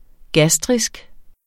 Udtale [ ˈgasdʁisg ]